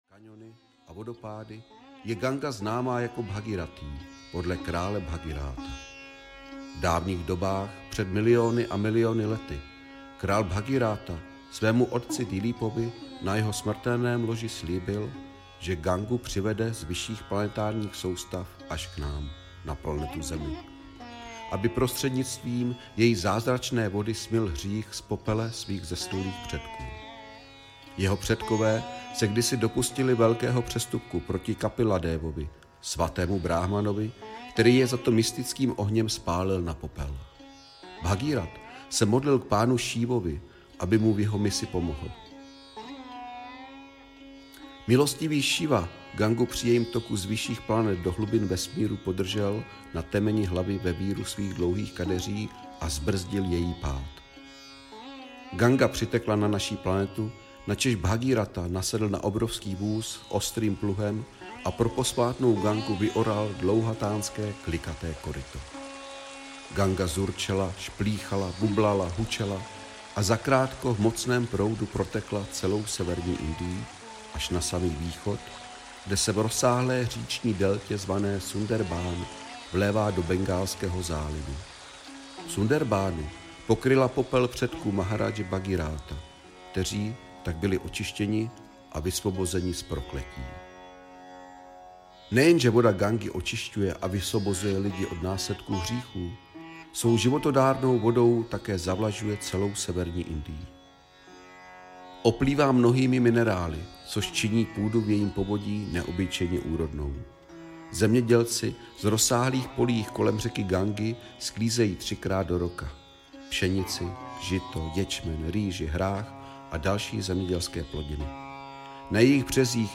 Krásný Bhágavatam díl 5. audiokniha
Ukázka z knihy